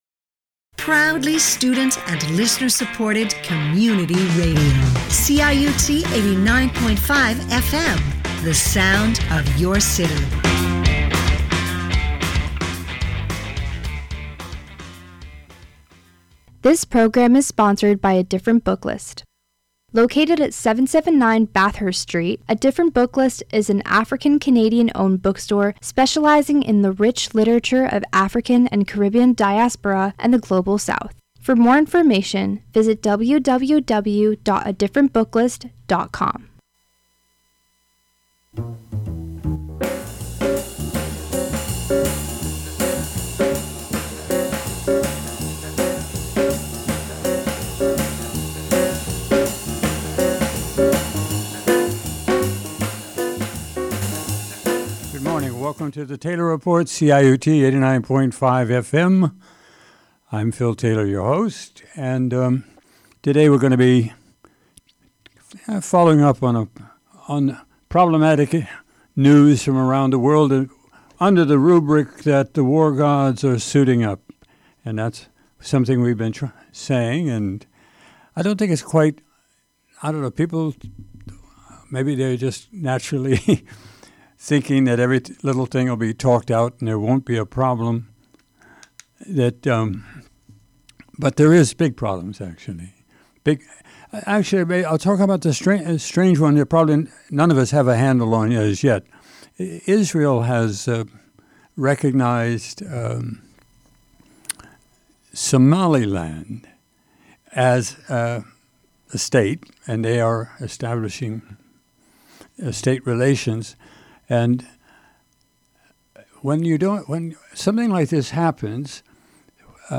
Taylor Report commentary